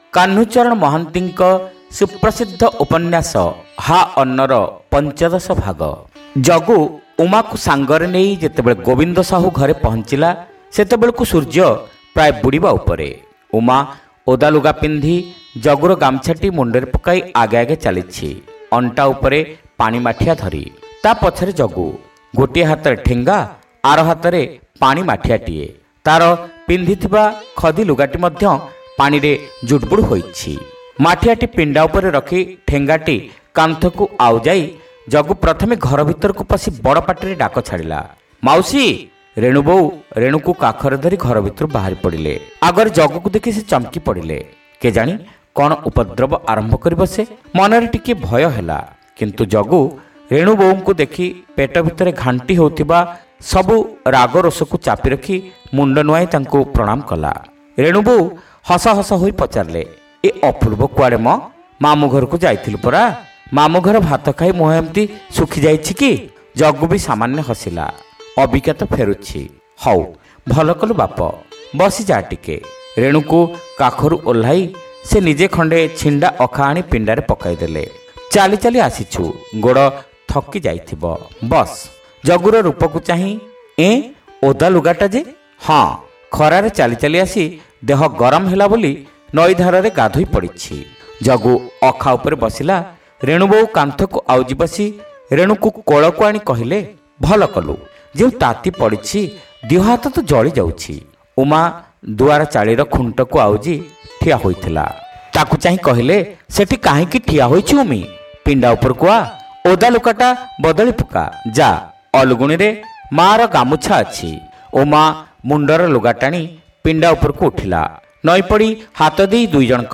Audio Novels : Ha Anna (Part-15)